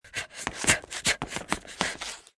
avatar_emotion_taunt.mp3